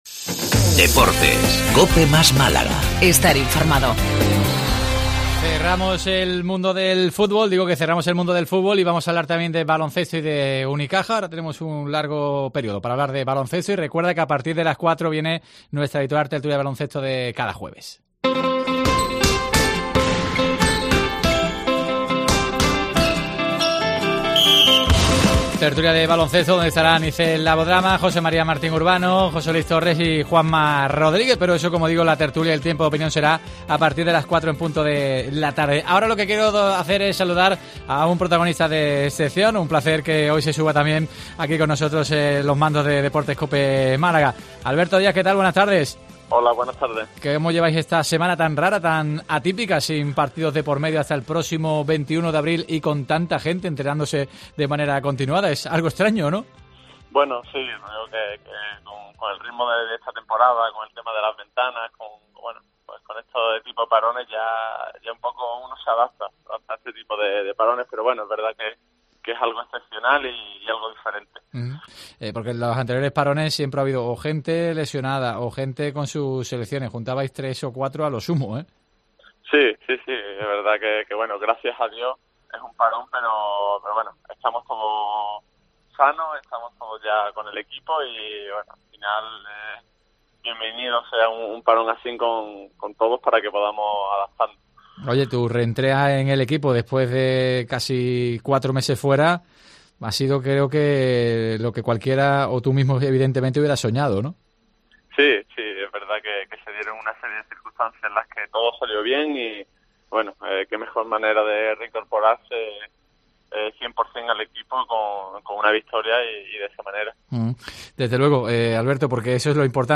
El jugador de Unicaja repasó en los micrófonos de COPE Málaga su vuelta a las canchas tras casi cuantro meses de baja.